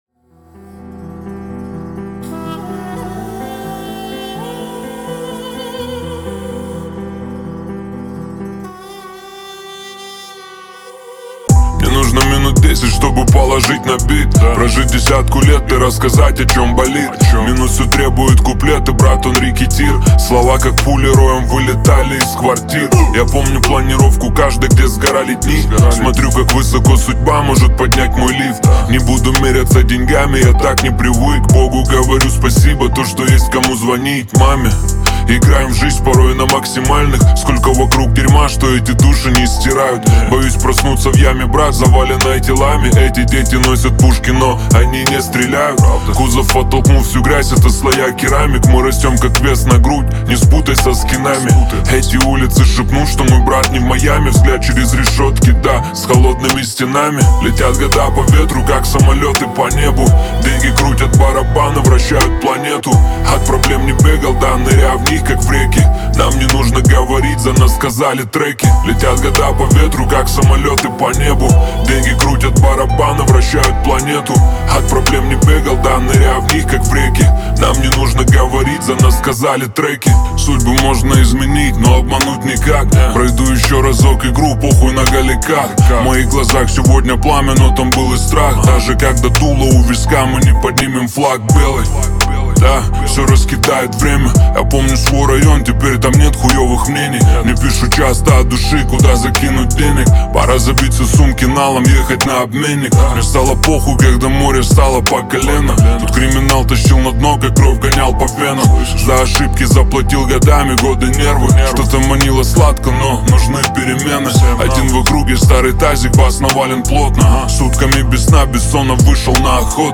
Качество: 320 kbps, stereo
Поп музыка, Русские треки